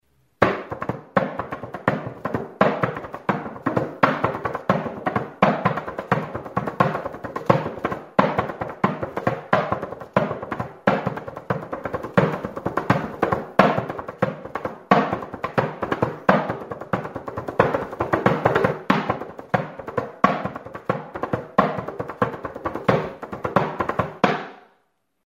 Membranophones -> Beaten -> Tambourines
FANDANGO ERRITMOA.
Recorded with this music instrument.
PANDERO; PANDERETA
Txindarik gabeko partxe bateko pandero borobila da.